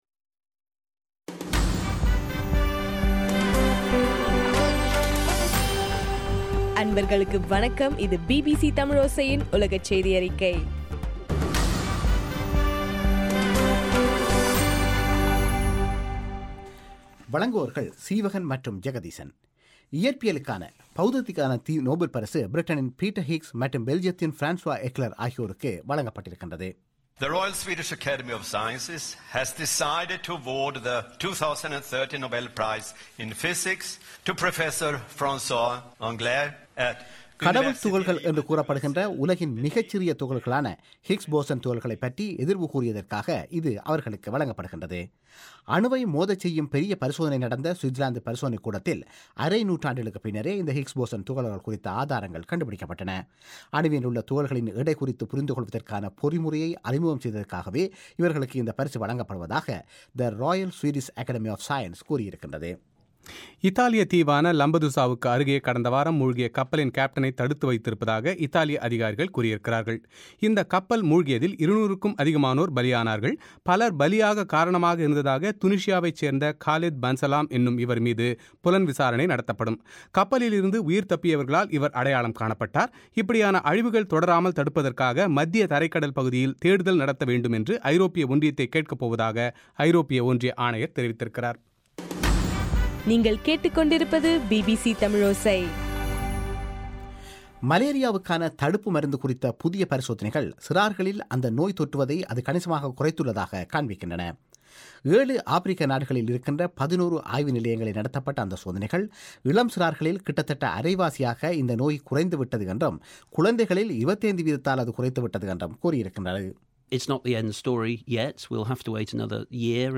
அக்டோபர் 8 பிபிசி தமிழோசை உலகச்செய்தி அறிக்கை